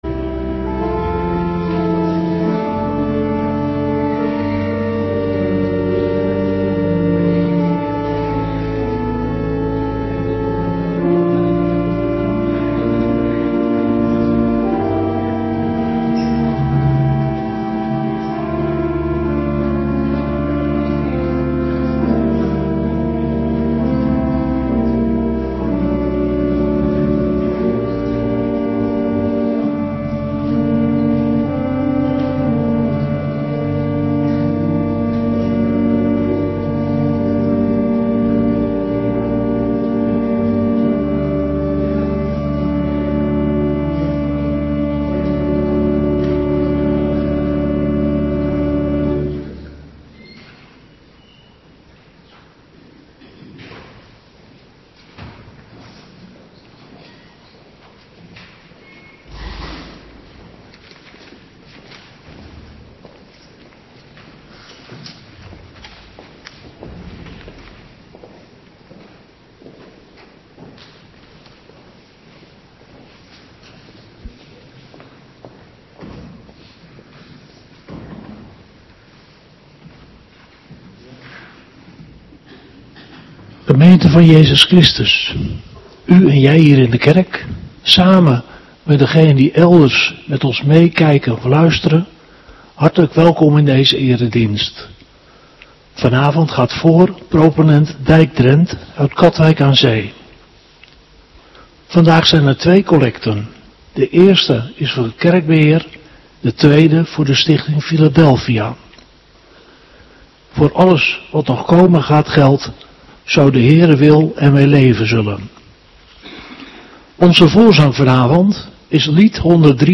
Avonddienst 15 februari 2026